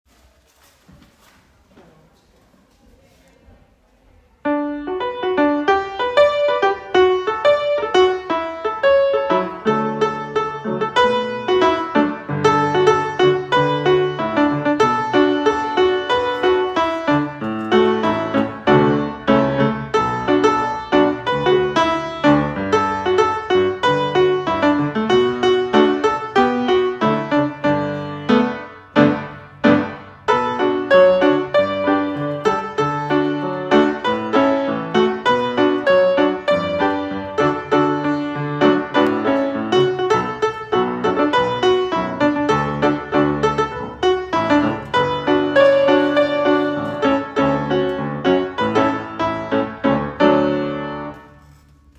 Audition Song Backing Track: (